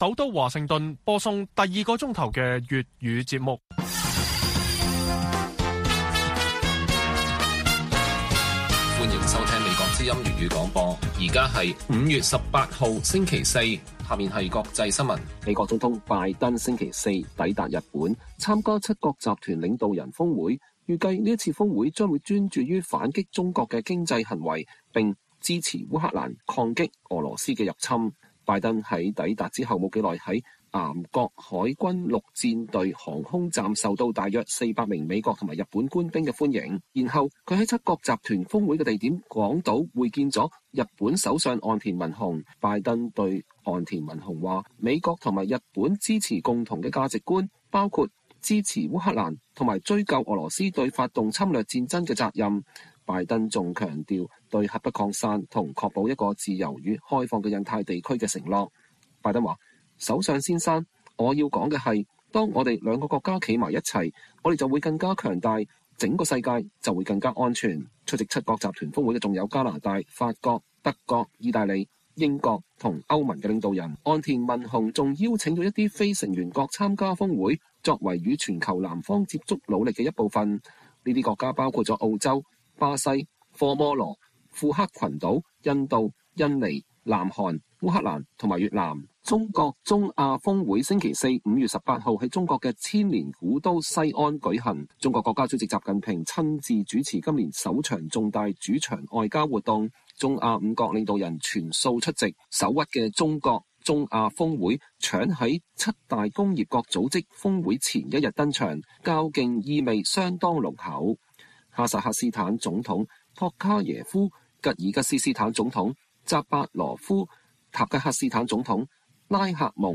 粵語新聞 晚上10-11點: 拜登抵達日本參加七國集團峰會